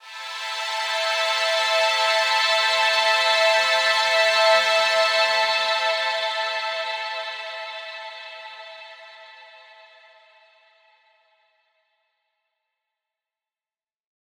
SaS_HiFilterPad07-A.wav